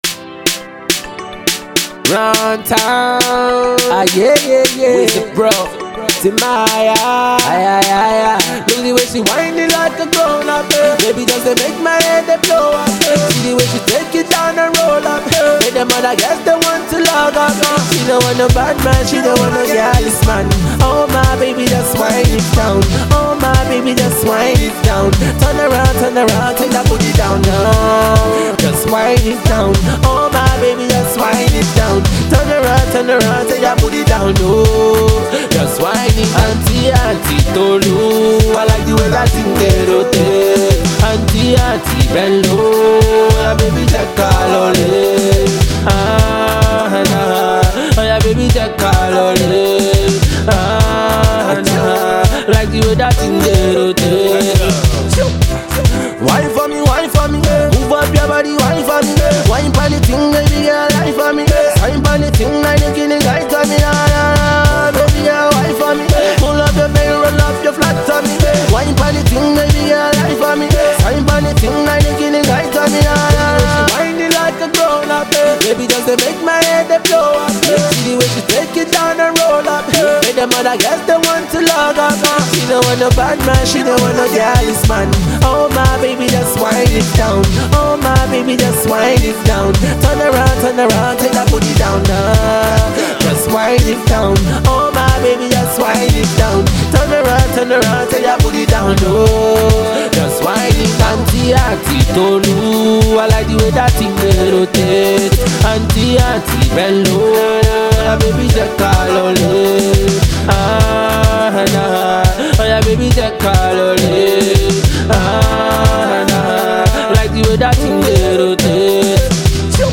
Dancehall meets Afro Pop tune